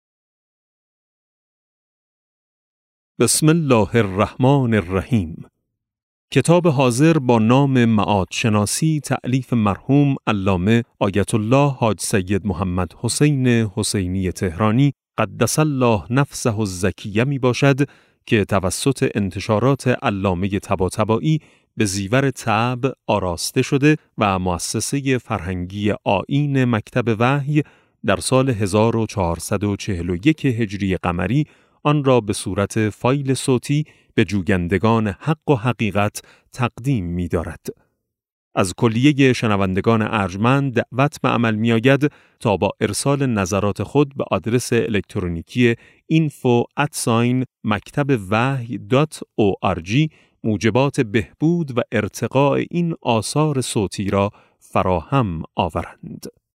کتاب صوتی معاد شناسی ج9 - جلسه0